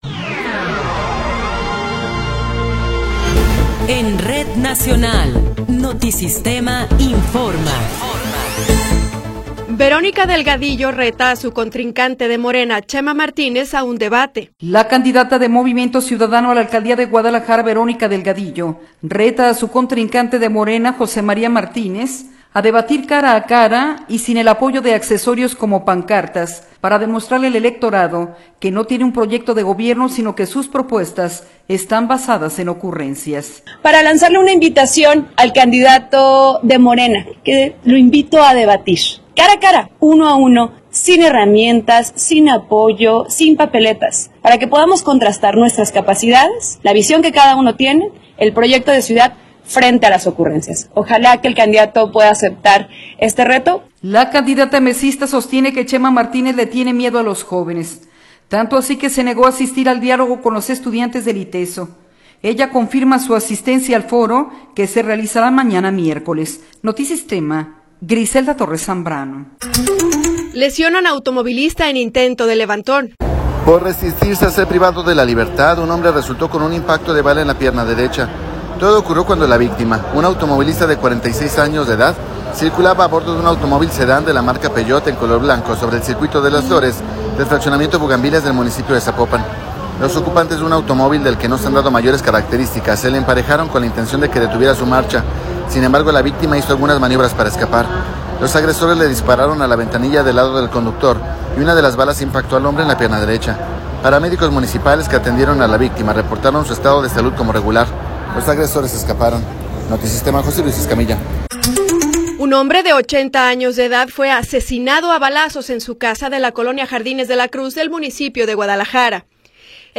Noticiero 15 hrs. – 16 de Abril de 2024
Resumen informativo Notisistema, la mejor y más completa información cada hora en la hora.